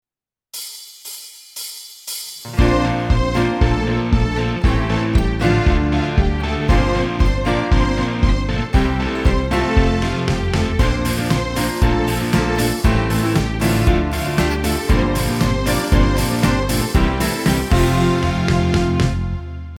Intro (F)